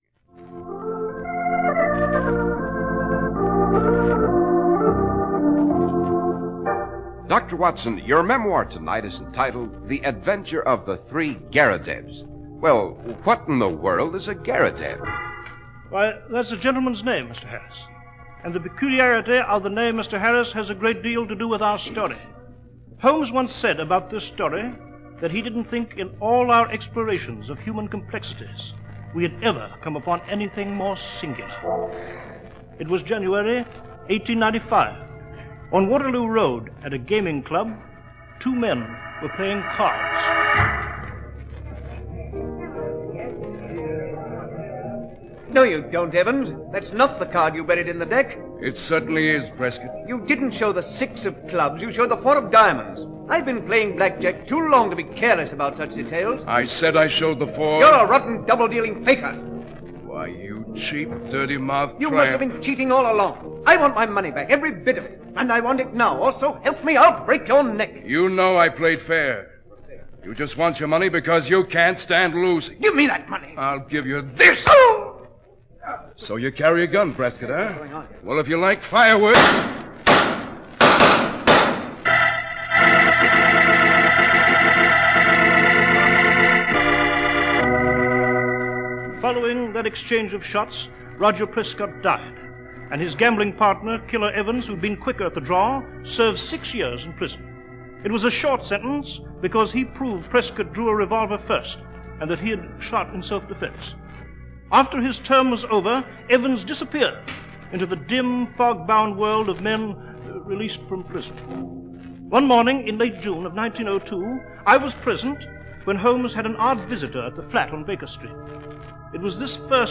Sherlock Holmes Radio Shows - Sherlock Holmes Public Library